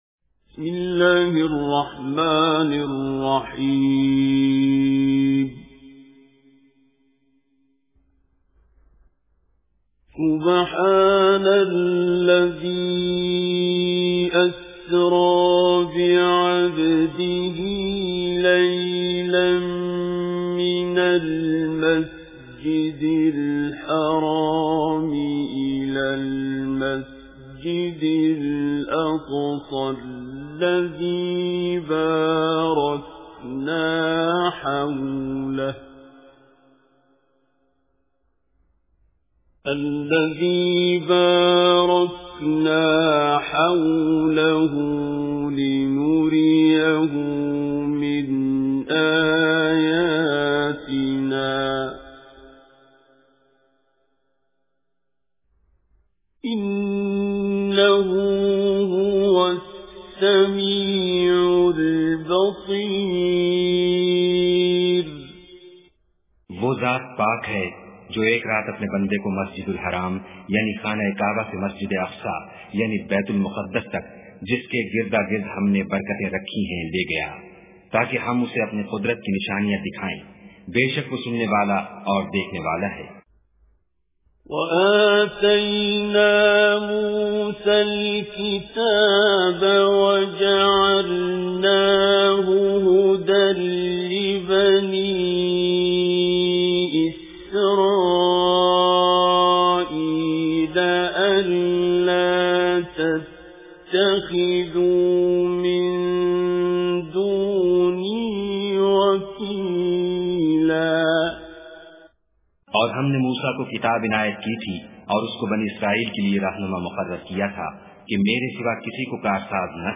Surah Al-Isra Tilawat With Urdu Translation